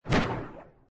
magicShoot.mp3